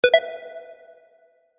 incoming-message-hSR_mn6D.mp3